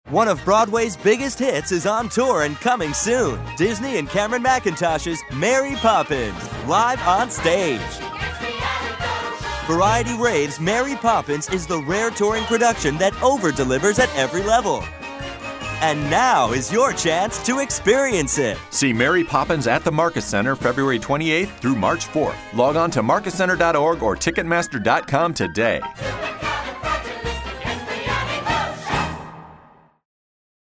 Mary Poppins Radio Commercial